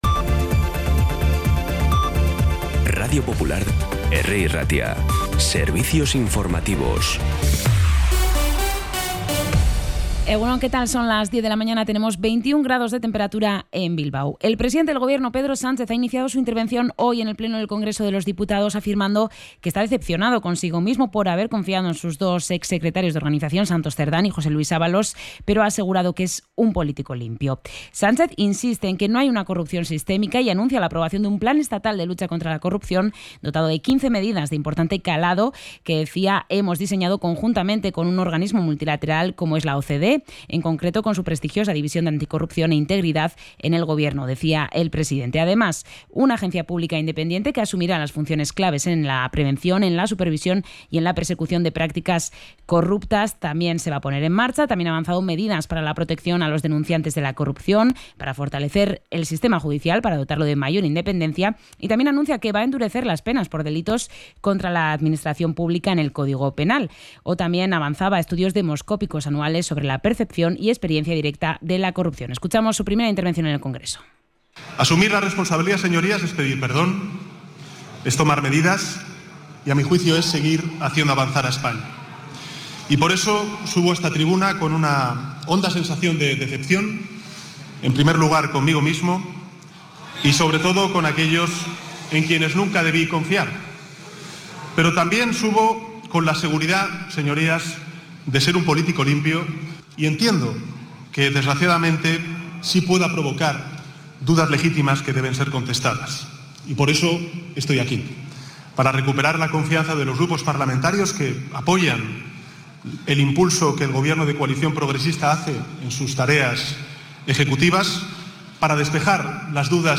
Información y actualidad desde las 10 h de la mañana